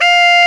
Index of /90_sSampleCDs/Roland L-CD702/VOL-2/SAX_Tenor mf&ff/SAX_Tenor ff
SAX TENORF0W.wav